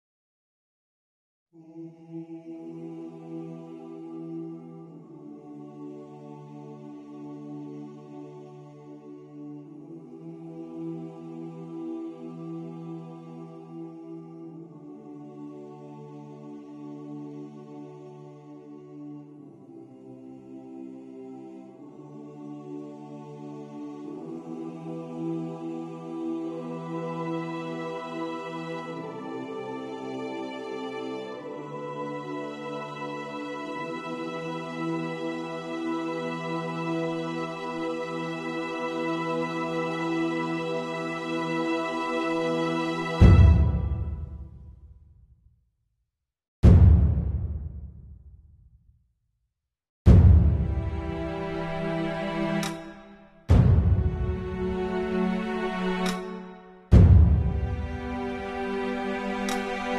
Mechanical Robot Bee Green Screen